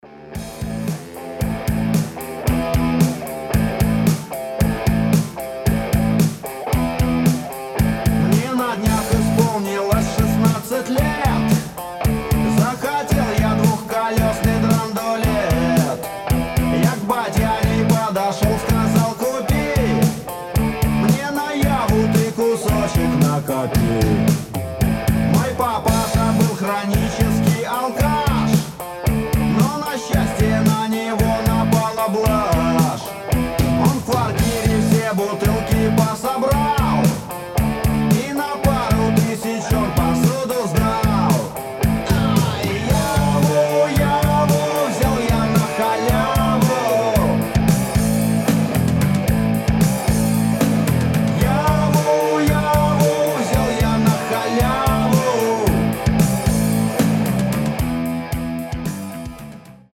Рок рингтоны
Панк-рок